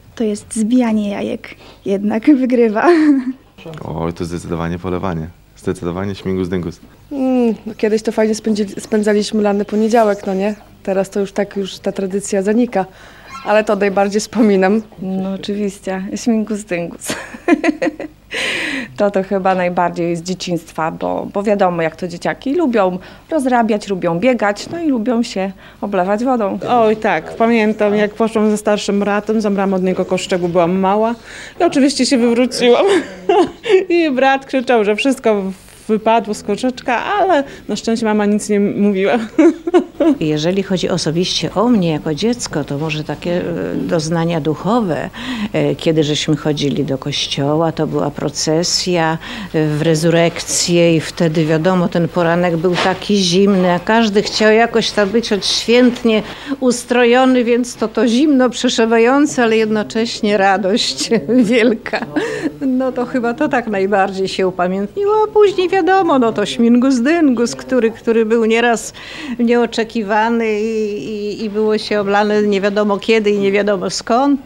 O ulubionych wielkanocnych tradycjach rozmawialiśmy z mieszkańcami Suwalszczyzny.